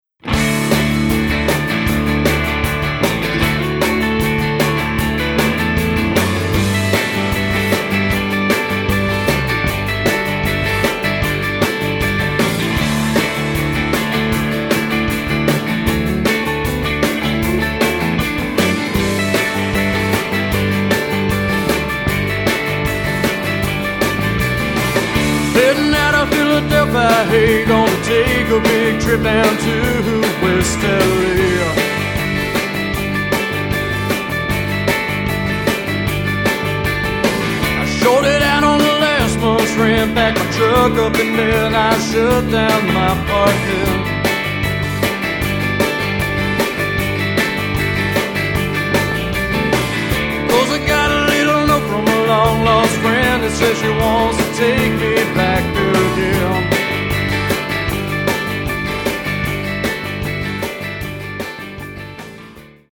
The tracks were recorded primarily "live"
stripped down and hard-rocking.